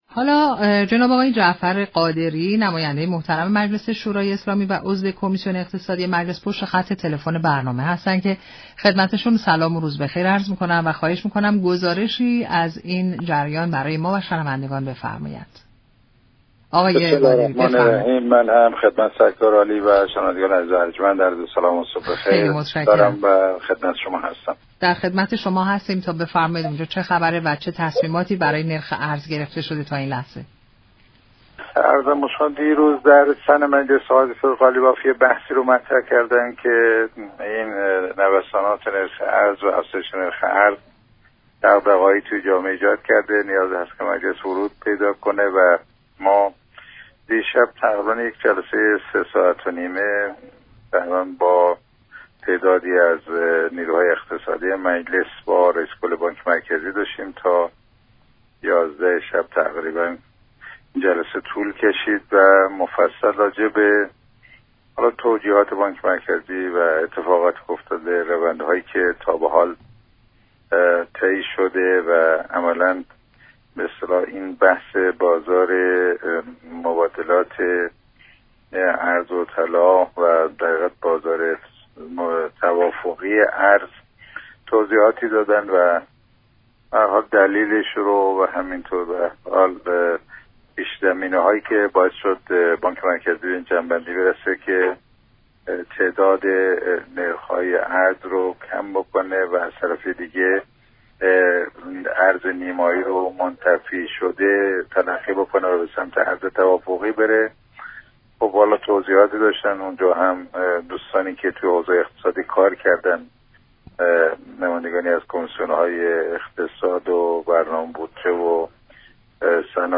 عضو كمیسیون اقتصادی مجلس در برنامه نمودار گفت: پایان سال میلادی، تحولات منطقه، محدودیت در فروش نفت و ناترازی‌های موجود نرخ ارز را بالا برده است.